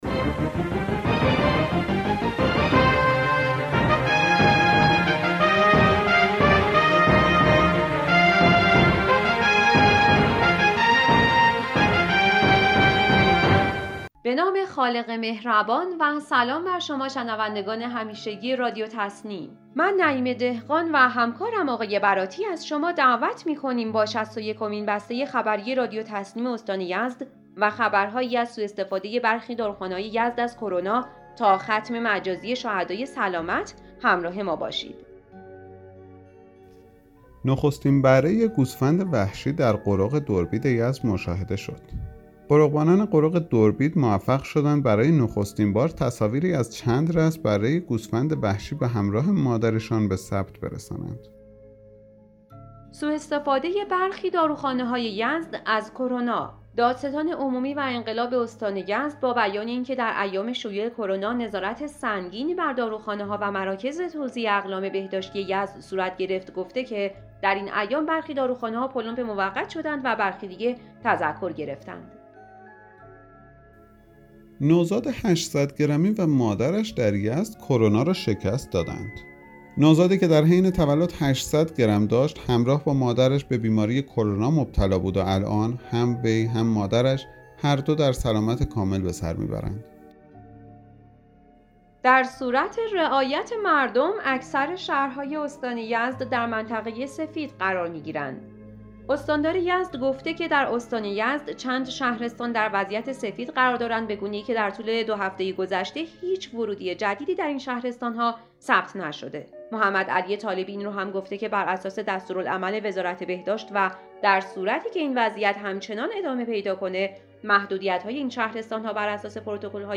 به گزارش خبرگزاری تسنیم از یزد, شصت و یکمین بسته خبری رادیو تسنیم استان یزد با خبرهایی از سواستفاده برخی داروخانه‌های یزد از کرونا, ثبت نخستین تصاویر از بره گوسفند وحشی در قرق دربید یزد، وضعیت سفید برای برخی شهرهای یزد, انجام سم پاشی تصفیه خانه فاضلاب در یزد, مشارکت بندرخشک پیشگامان در رزمایش کمک مومنانه، غلبه نوزاد 800 گرمی و مادرش در یزد بر کرونا ، برخورد جدی با اصناف متخلف و ختم مجازی شهدای سلامت در هلال احمر یزد منتشر شد.